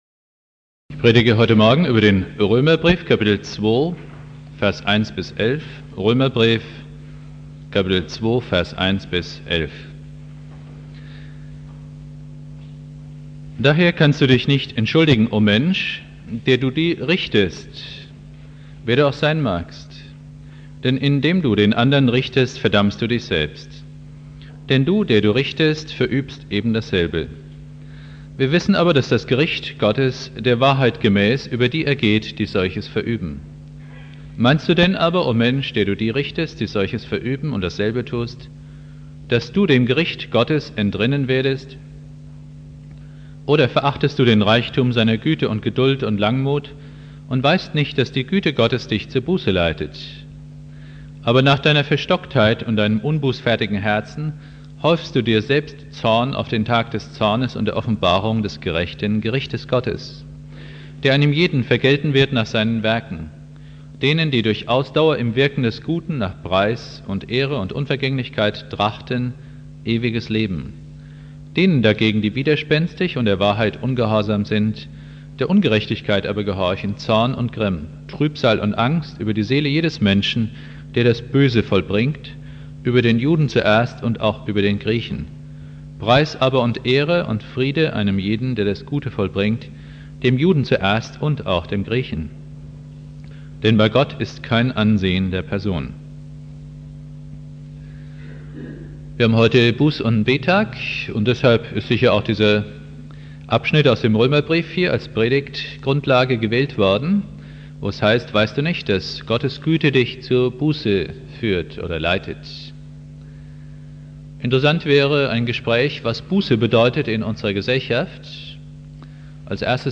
Predigt
Buß- und Bettag Prediger